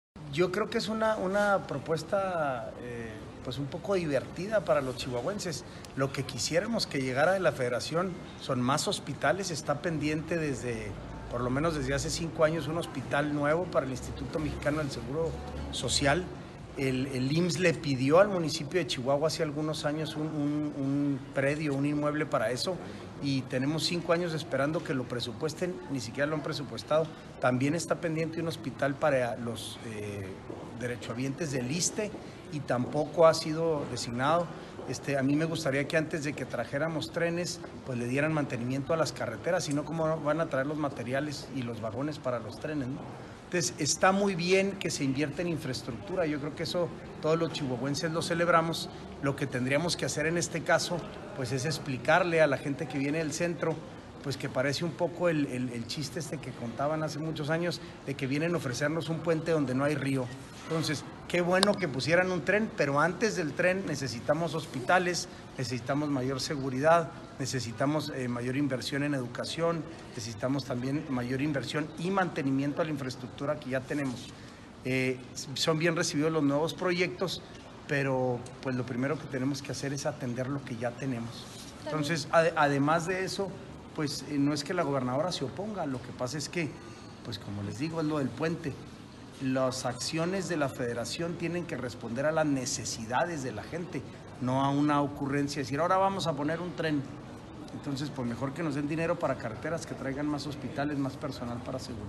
AUDIO: SANTIAGO DE LA PEÑA, TITULAR DE LA SECRETARÍA GENERAL DE GOBIERNO